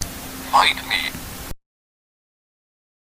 contribs)Meta Knight's sound clip when selected with a Wii Remote.